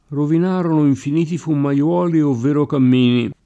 fumaiolo [fumaL0lo] s. m. — oggi lett. fumaiuolo [fumaLU0lo], ant. fummaiuolo [fummaLU0lo]: rovinarono infiniti fummaiuoli, ovvero cammini [